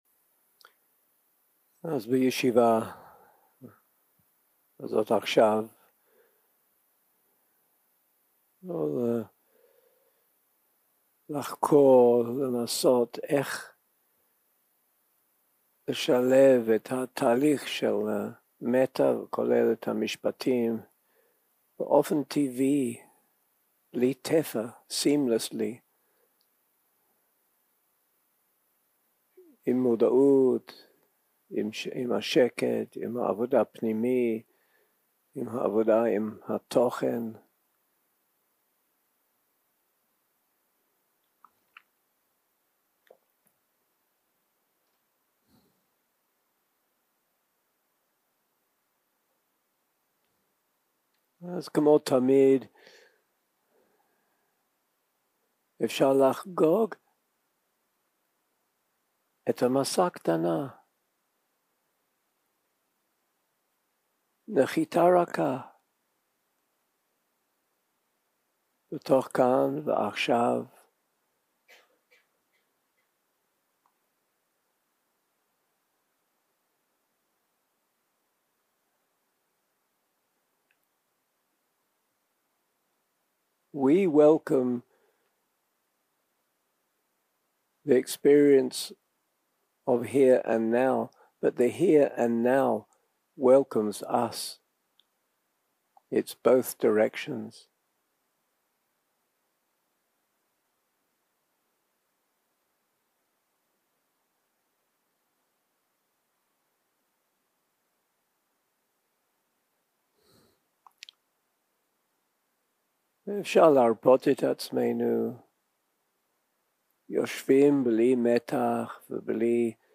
יום 4 - הקלטה 8 - צהרים - מדיטציה מונחית - מטא ומודעות